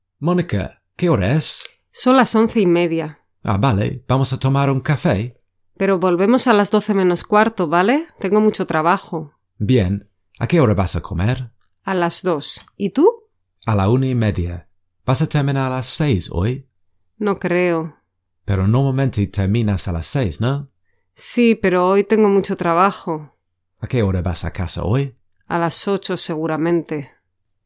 24.-Listening-Practice-Asking-and-telling-the-time-Part-1.mp3